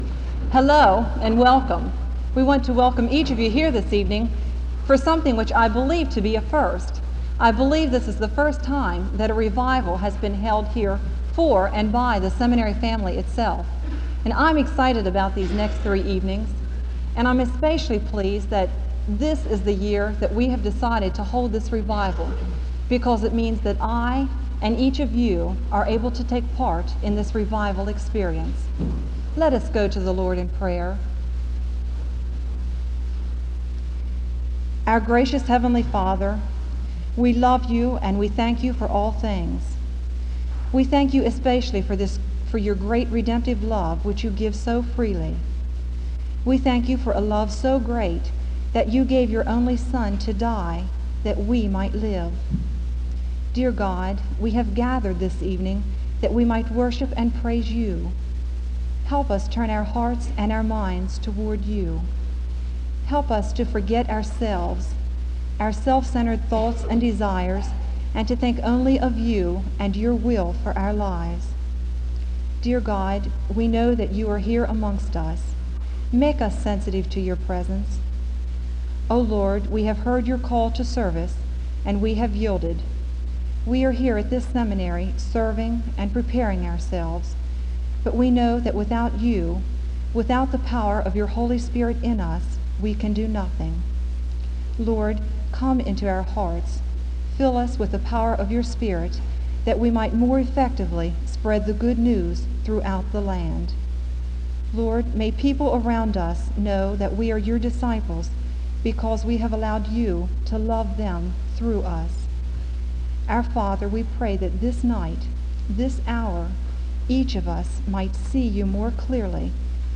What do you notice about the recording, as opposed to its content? SEBTS Chapel and Special Event Recordings